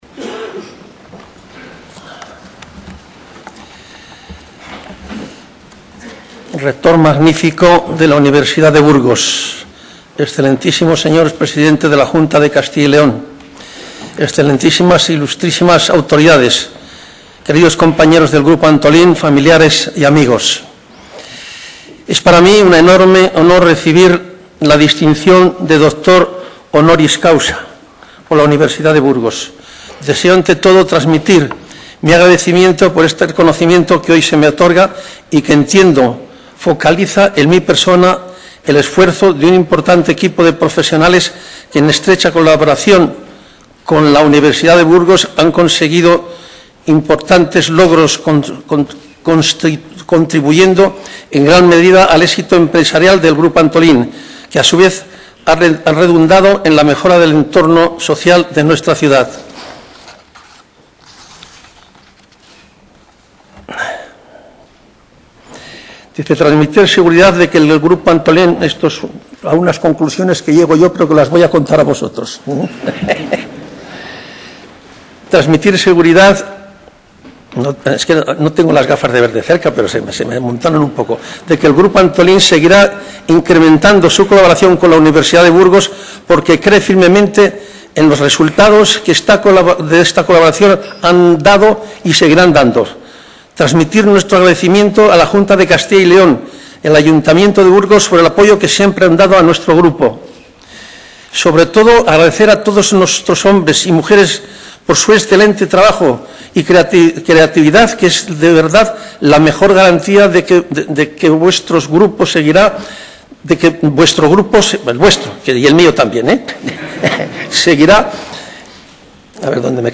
La Universidad rememora el tradicional y vistoso ritual con la puesta en escena de la incorporación del empresario al Claustro y el juramento como nuevo doctor.
Discurso